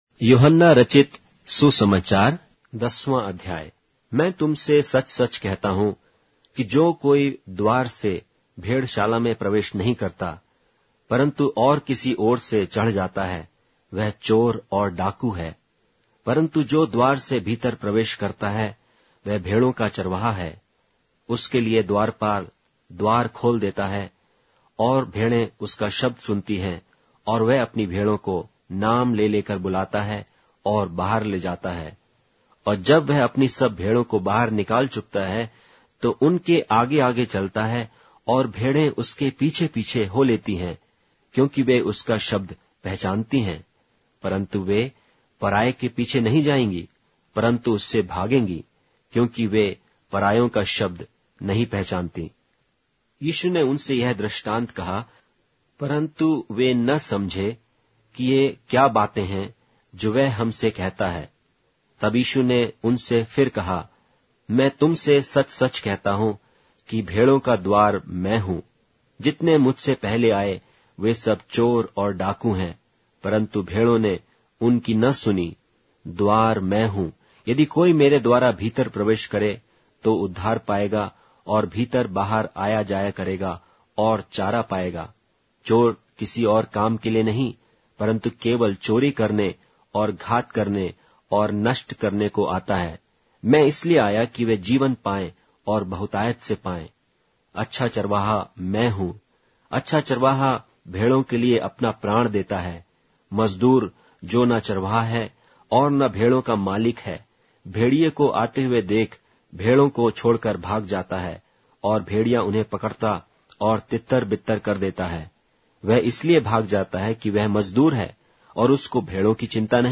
Hindi Audio Bible - John 17 in Gnterp bible version